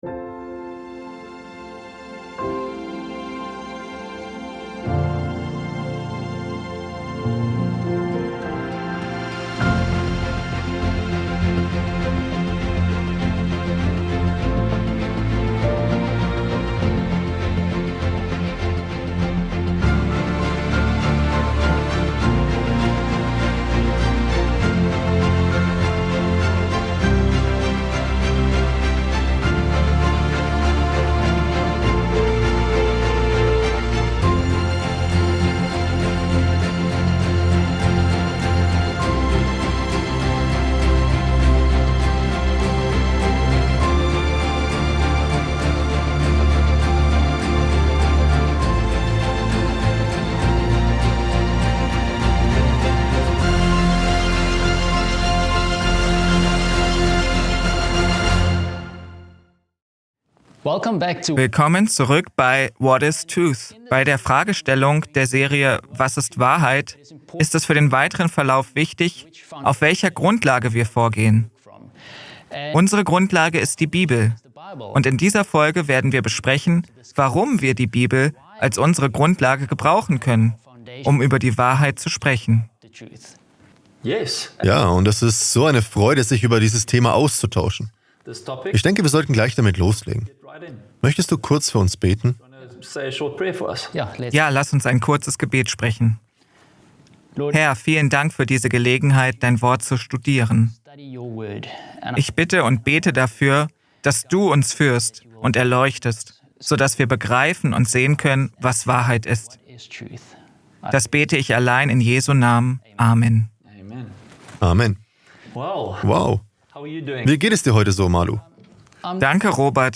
In diesem packenden Vortrag wird die Kraft und Bedeutung der Heiligen Schrift thematisiert. Sie fungiert als unverzichtbares Werkzeug im Leben der Gläubigen, um in schwierigen Zeiten Standhaftigkeit zu finden. Biblische Wahrheiten zeigen Perspektiven auf, die Hoffnung, Glauben und eine tiefere Beziehung zu Gott fördern.